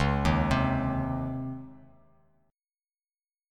DbM7sus2 Chord